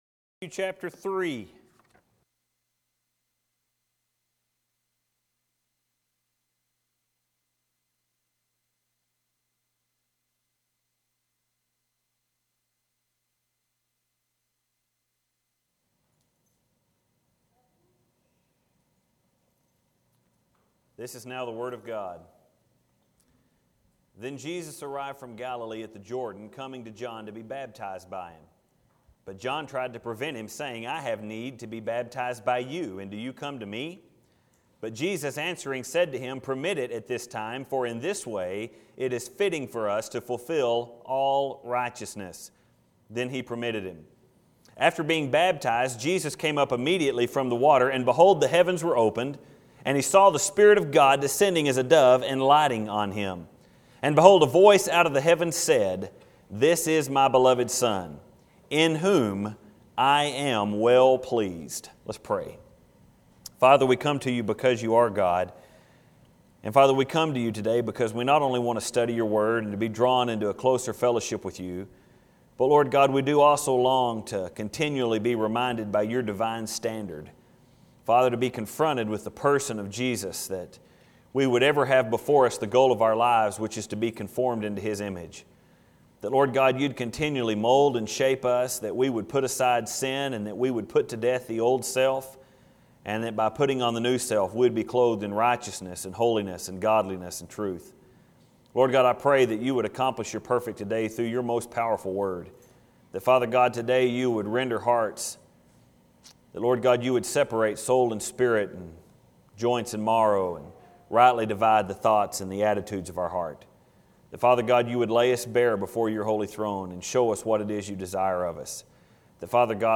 The Crowning of the King Matthew 3:13-17 The obvious title of this sermon would be “The Baptism of Jesus”, After all, that is the incident that occurs in our text.